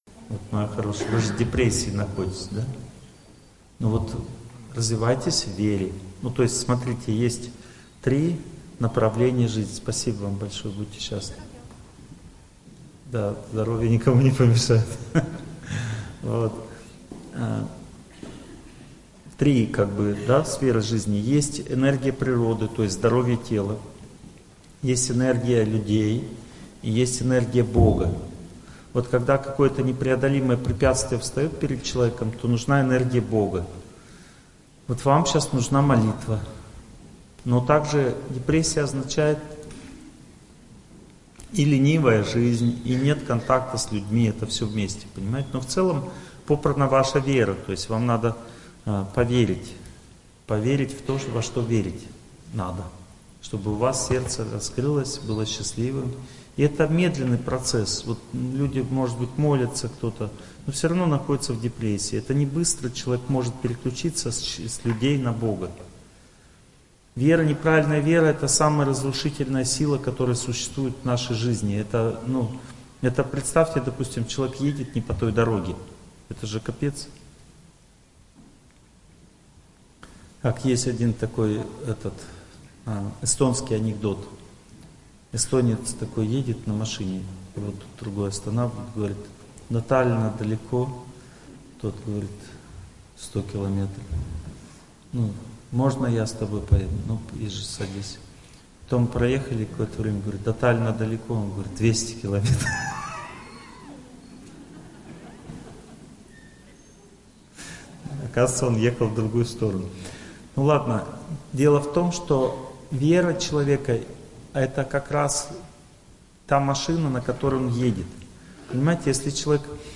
Аудиокнига Чувство собственного достоинства, судьба, успех. Часть 3 | Библиотека аудиокниг